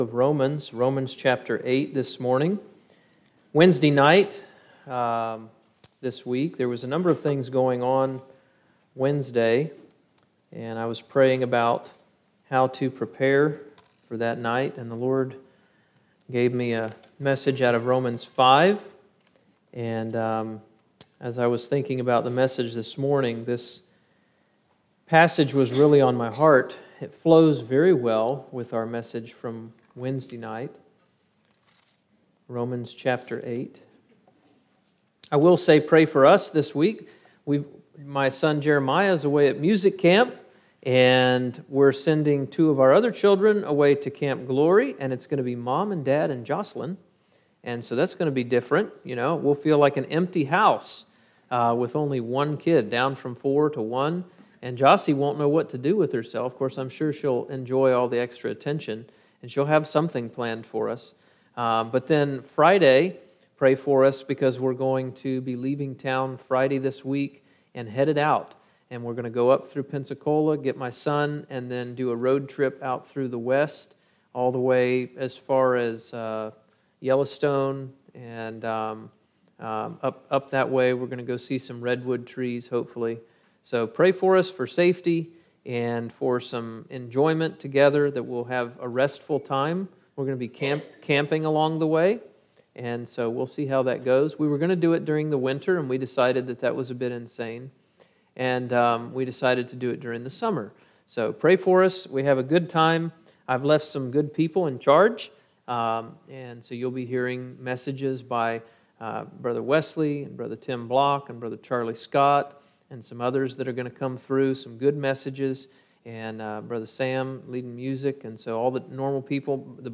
From Series: "Topical Messages"